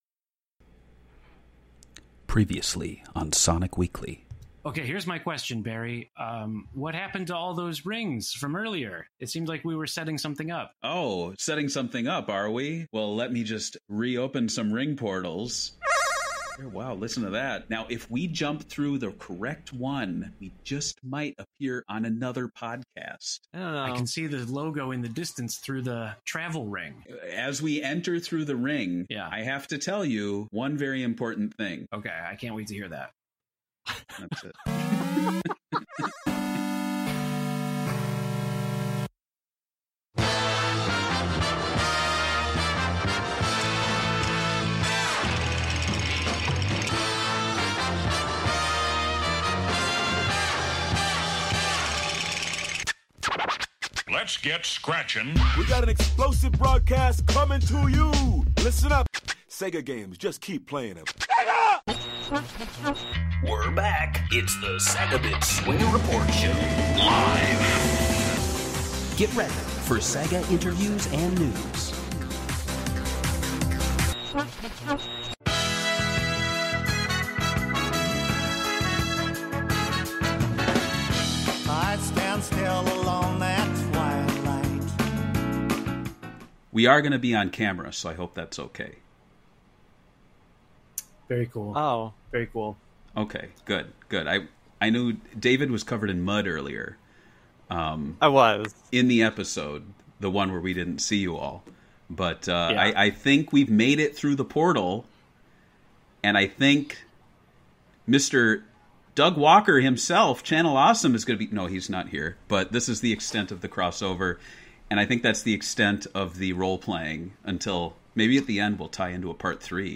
Sonic Weekly CrossPod _ The SEGAbits Swingin' Report Show LIVE.mp3